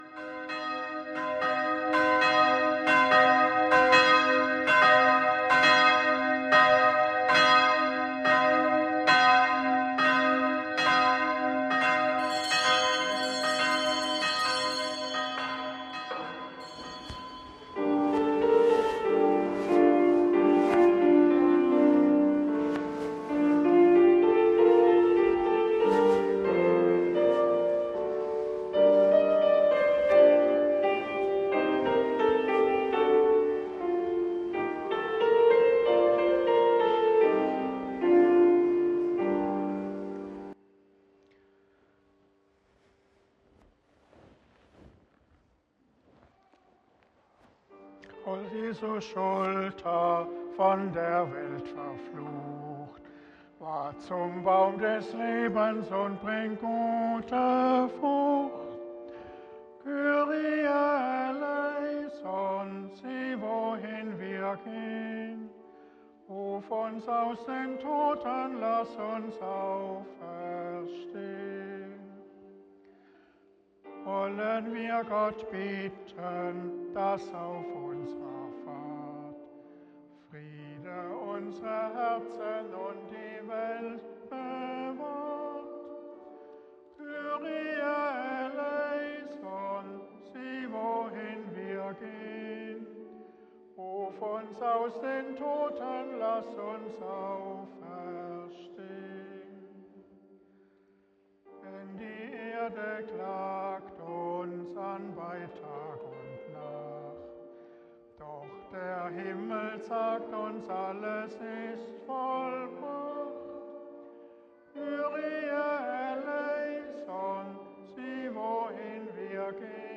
Aufzeichnung des Gottesdienstes vom 3. September 2023